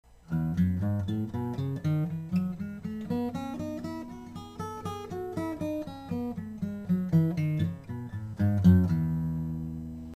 E Major Scale
E scale-1 time-slow